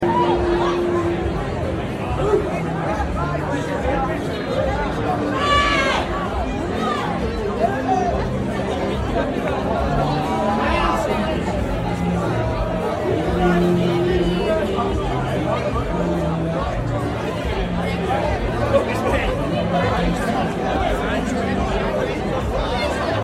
The very popular Temple Bar area in Dublin, Ireland.